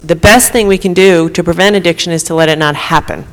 RHODE ISLAND GOVERNOR GINA RAIMONDO SPEAKING AT INTERNATIONAL OPIOD CONFERENCE AT HARVARD MEDICAL SCHOOL RECOMMENDS RESTRICTING PRESCRIPTIONS OF OPIOIDS.